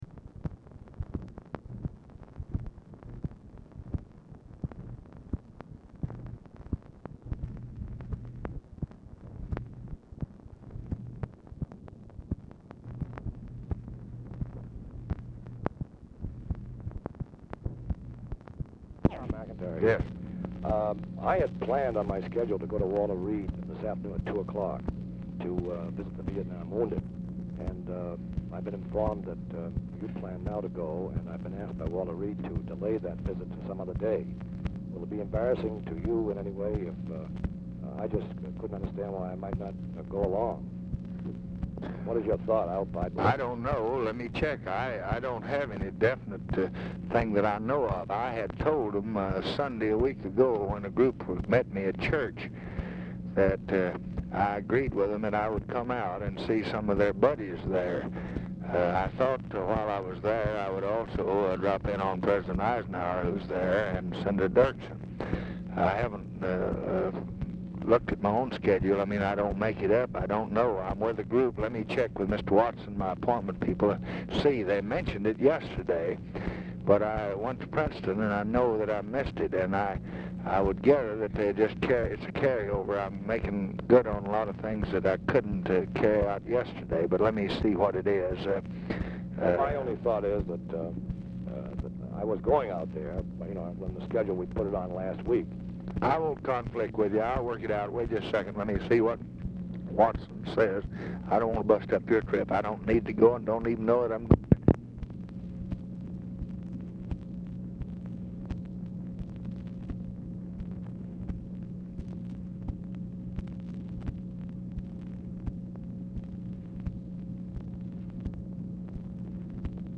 Telephone conversation # 10117, sound recording, LBJ and THOMAS MCINTYRE, 5/12/1966, 12:46PM
MACHINE NOISE PRECEDES CALL; LBJ PUTS MCINTYRE ON HOLD DURING CALL TO CHECK HIS SCHEDULE
Dictation belt